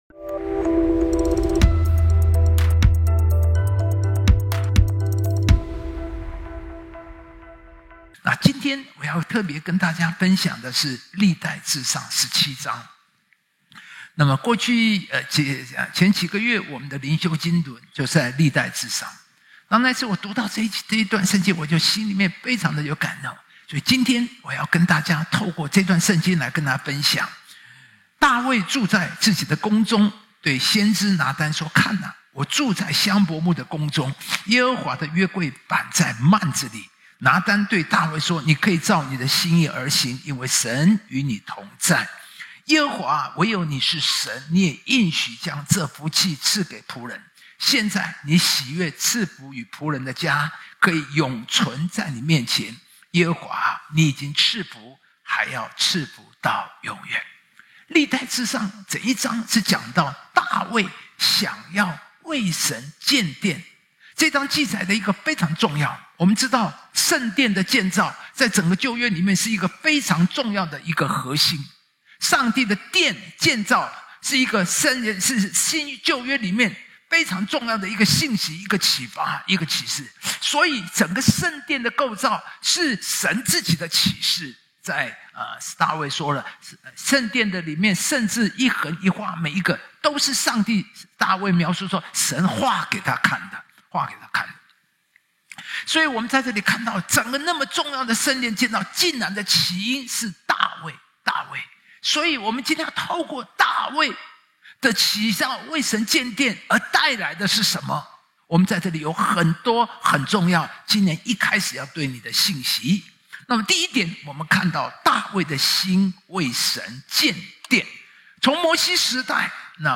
【主日信息】